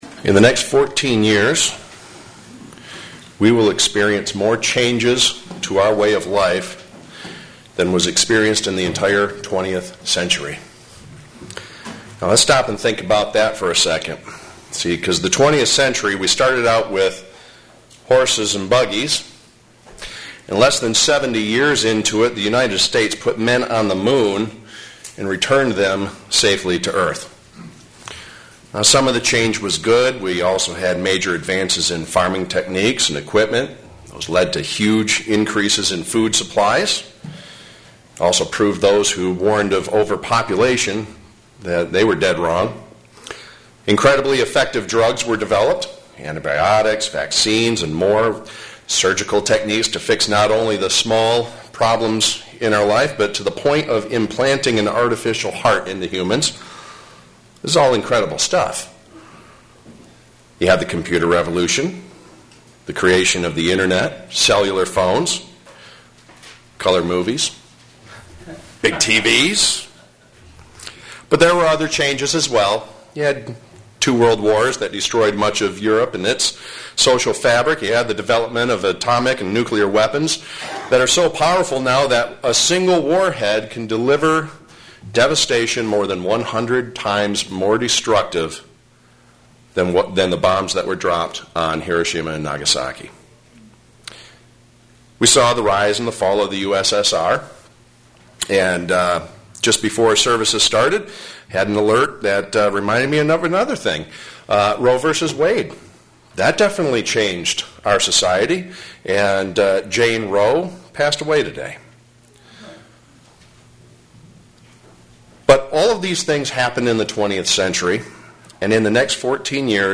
Given in Flint, MI
What is meditation and how do we meditate? sermon Studying the bible?